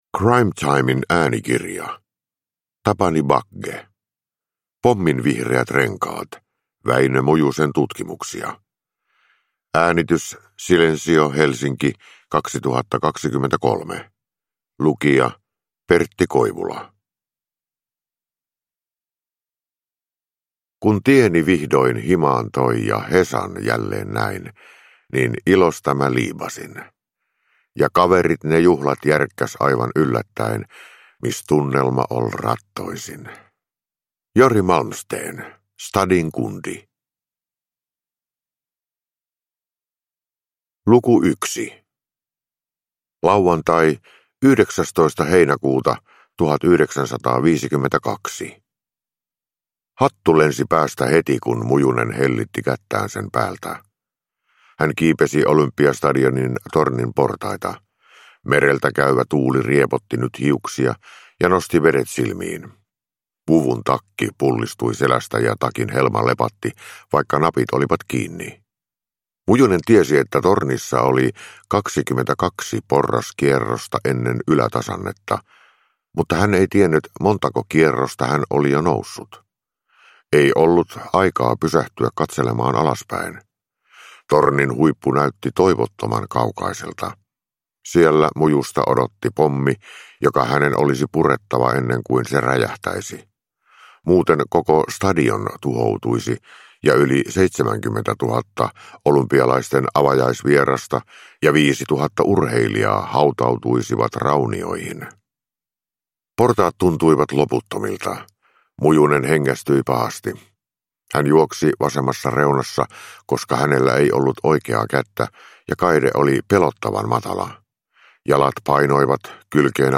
Pomminvihreät renkaat – Ljudbok – Laddas ner
Uppläsare: Pertti Koivula